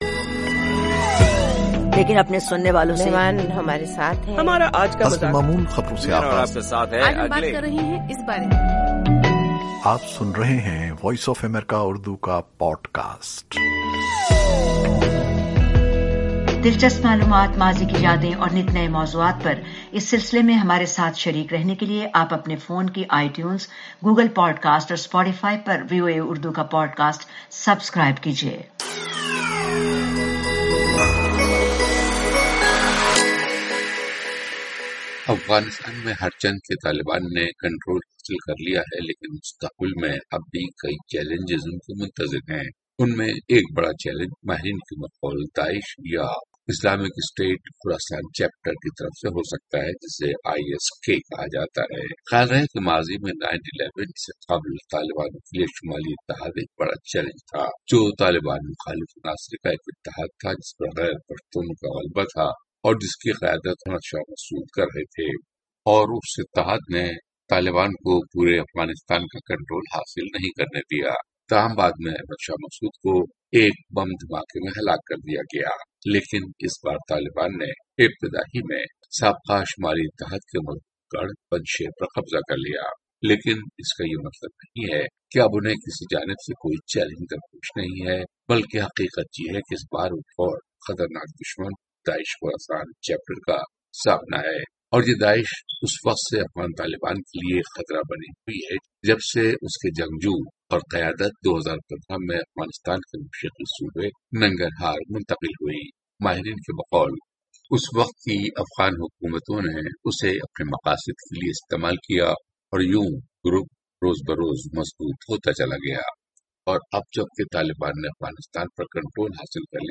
افغانستان میں طالبان نے کنٹرول حاصل کر لیا ہے لیکن مستقبل میں اب بھی کئی چیلنجز ان کے منتظر ہیں۔ ان میں ایک بڑا چیلنج ماہرین کے بقول داعش کی طرف سے ہو سکتا ہے۔ تجزیاتی رپورٹ